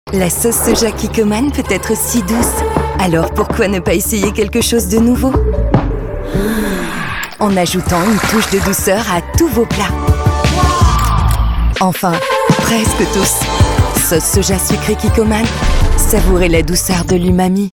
Voix off
Showreel Voix naturelle